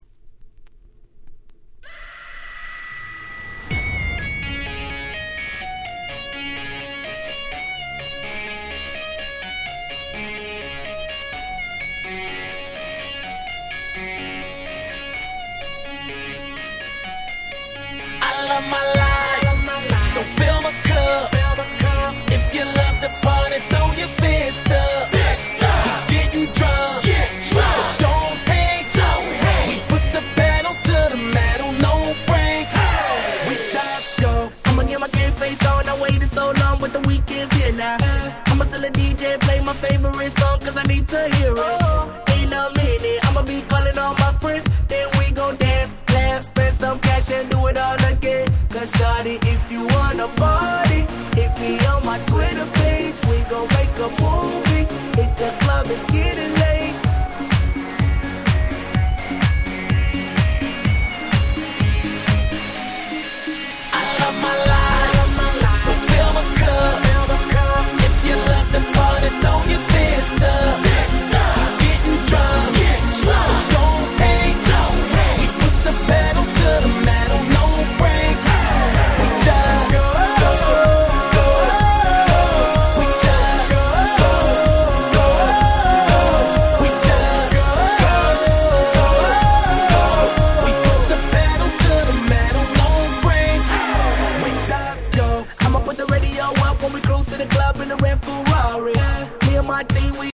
10' Smash Hit R&B !!